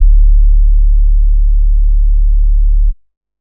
Bass (3).wav